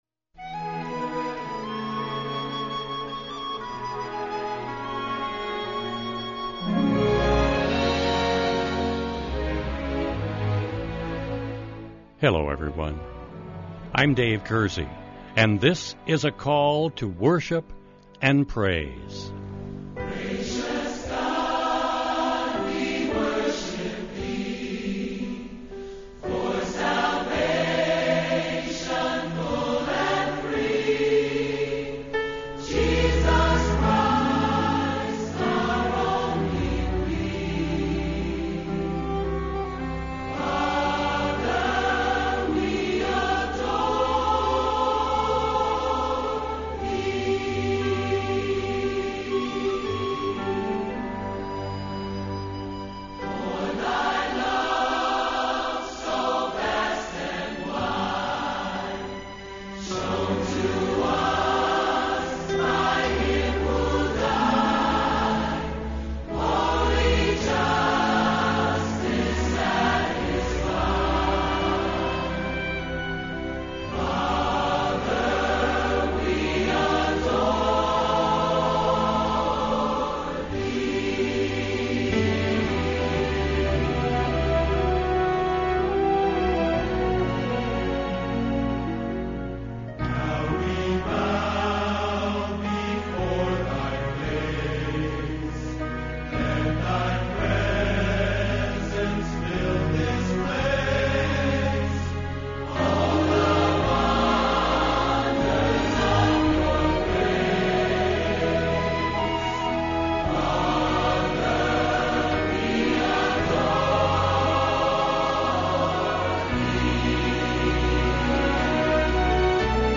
This week’s Devotional Topic This week here on Call To Worship we will be singing and sharing about the blessings we receive when we spend time each day meditating on God’s Holy Word.